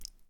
water-flick-tiny
bath bubble burp click drain drip drop effect sound effect free sound royalty free Sound Effects